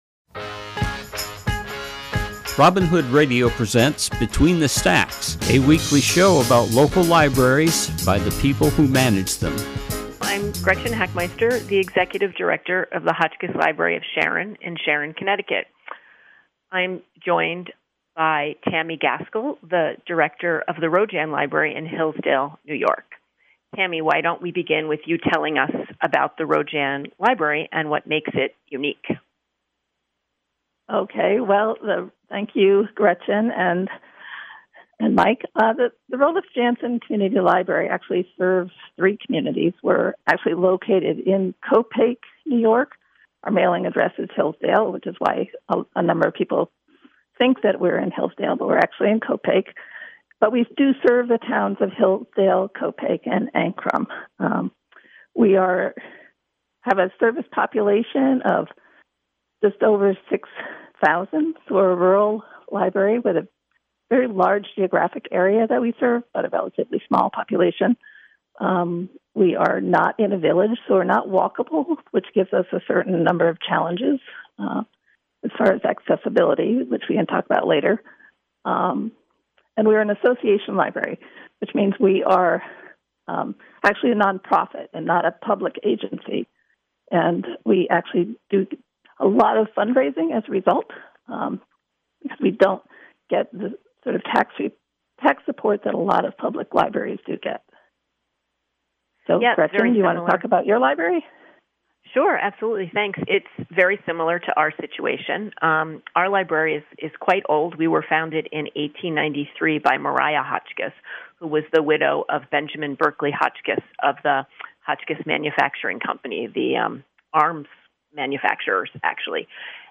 This program is a conversation